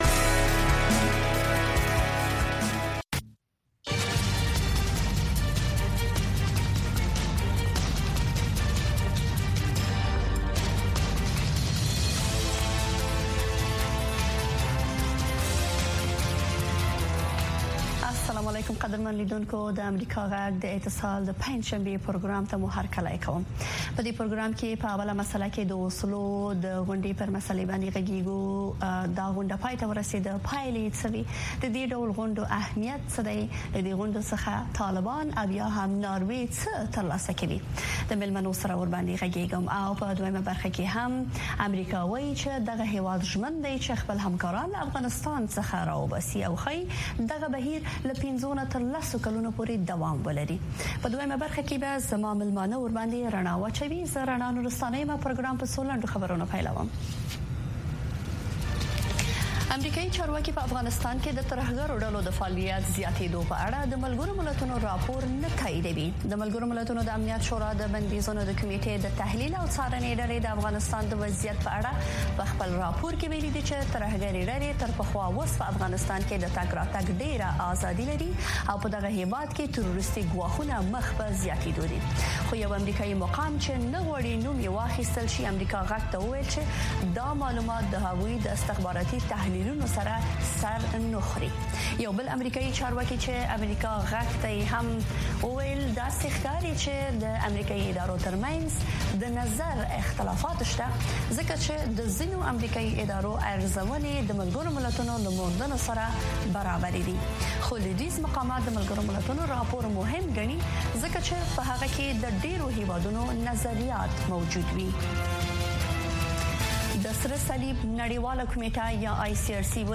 په دې خپرونه کې د چارواکو، شنونکو او خلکو سره، مهمې کورنۍ او نړیوالې سیاسي، اقتصادي او ټولنیزې مسئلې څېړل کېږي. دغه نیم ساعته خپرونه له یکشنبې تر پنجشنبې، هر مازدیګر د کابل پر شپږنیمې بجې، په ژوندۍ بڼه خپرېږي.